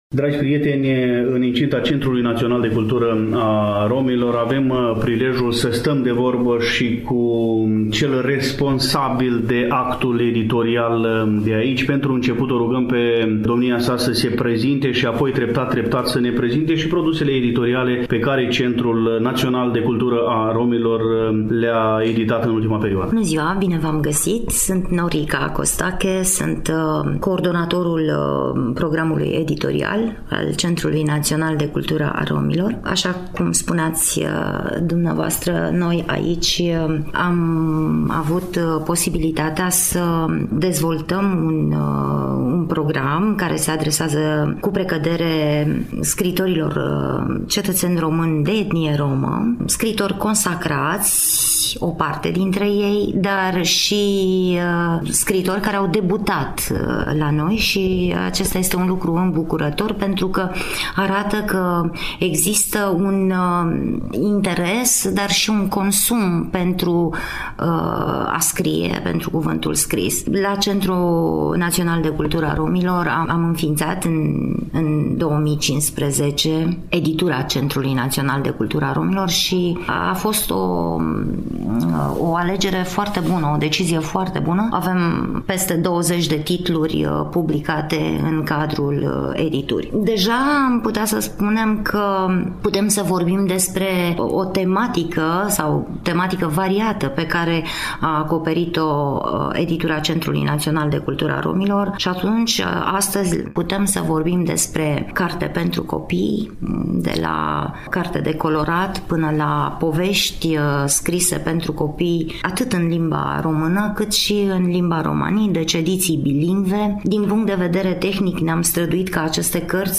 Invitata noastră în prima secvență audio pe care o postăm ne prezintă programul pe care l-am amintit, cu accent pe obiectivele acestuia.